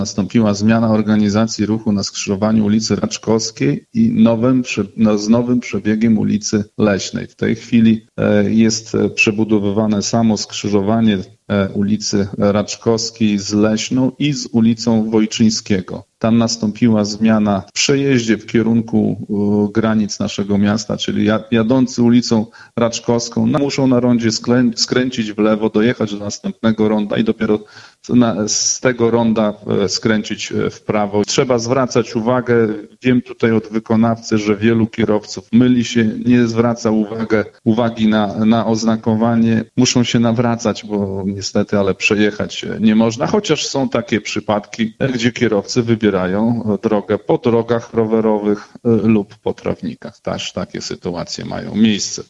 na antenie Radia 5